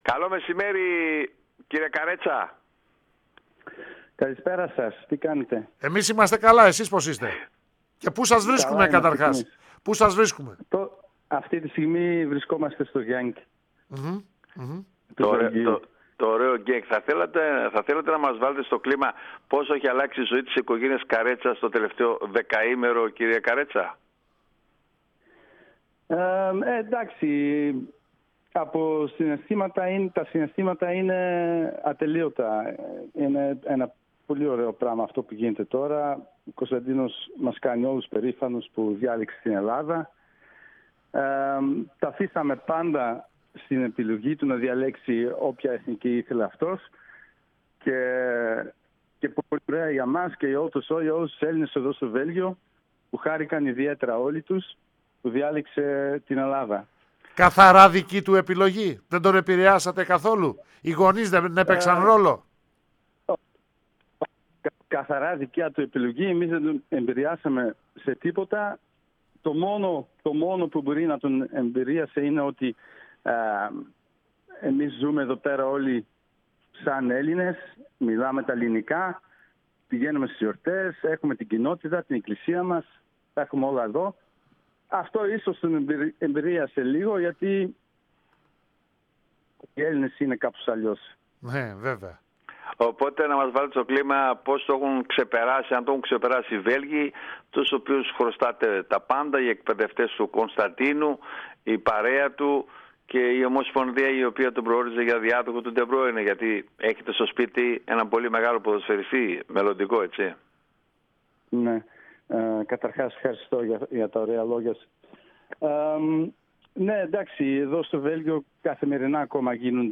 Μία πολύ ενδιαφέρουσα συζήτηση